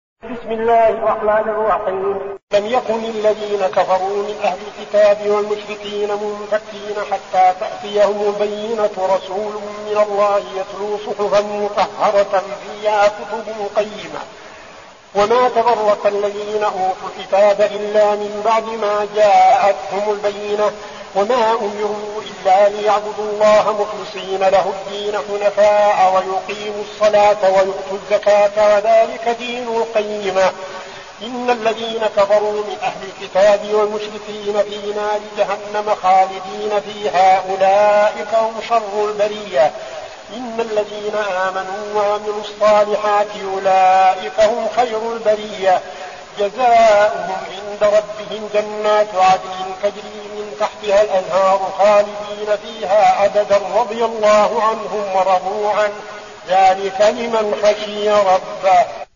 المكان: المسجد النبوي الشيخ: فضيلة الشيخ عبدالعزيز بن صالح فضيلة الشيخ عبدالعزيز بن صالح البينة